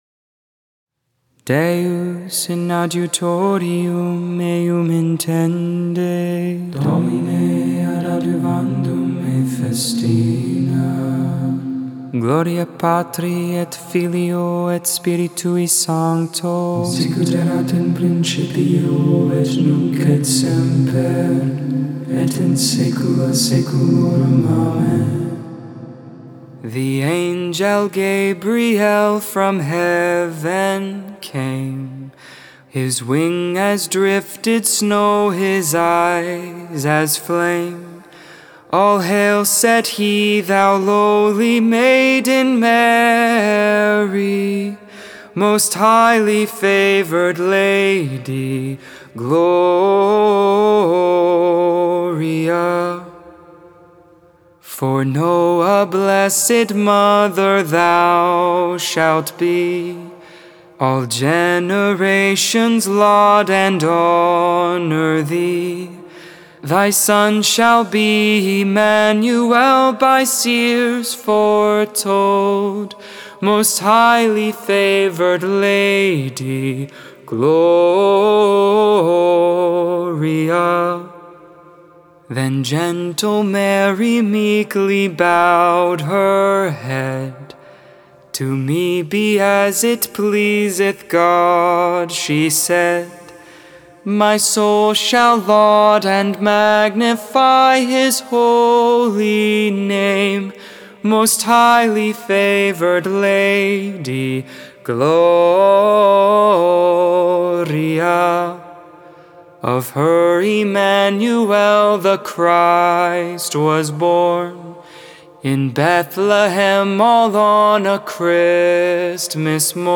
Solemnity of the Annunciation Lauds, Morning Prayer for Thursday of the 5th week of Lent.